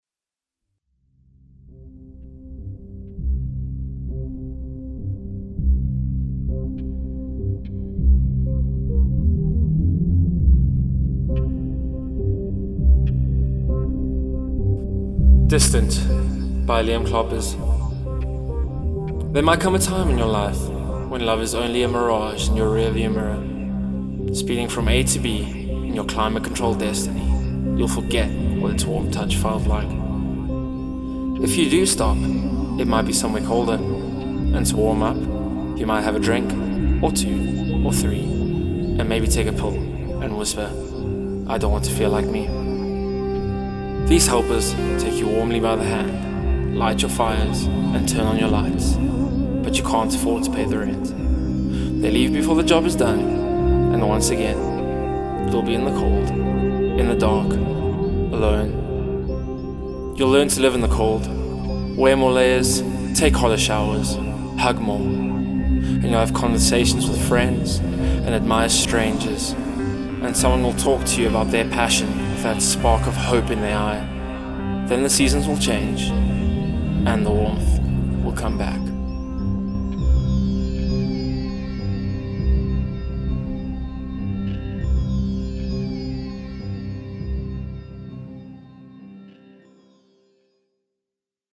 Sintetiese agtergrond vir organiese digkuns